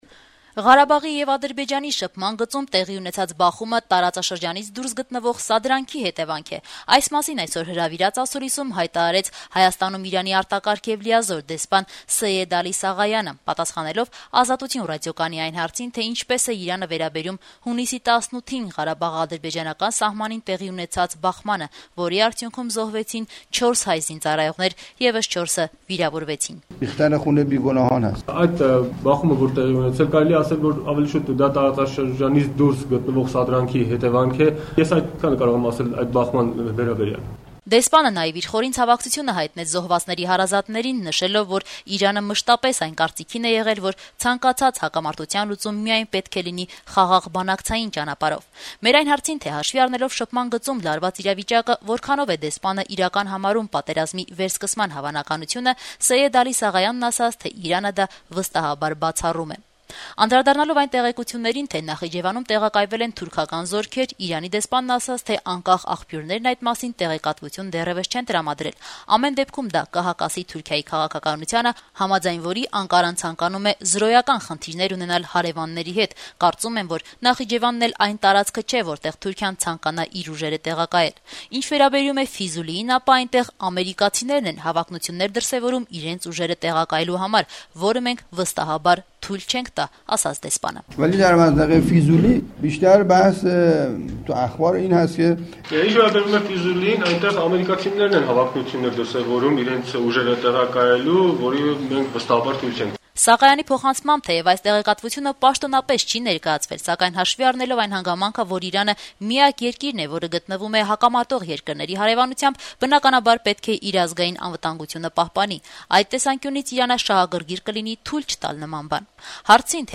Ղարաբաղի եւ Ադրբեջանի շփման գծում տեղի ունեցած բախումը տարածաշրջանից դուրս գտնվող սադրանքի հետեւանք է: Այդ մասին չորեքշաբթի օրը հրավիրած ասուլիսում հայտարարեց Հայաստանում Իրանի արտակարգ եւ լիազոր դեսպան Սեյեդ Ալի Սաղայիանը` պատասխանելով «Ազատություն» ռադիոկայանի հարցին, թե ինչպես է Իրանը վերաբերվում հունիսի լույս 19-ի գիշերը տեղի ունեցած բախմանը¸ որի արդյունքում զոհվեցին 4 հայ զինծառայողներ¸ եւս 4-ը վիրավորվեցին: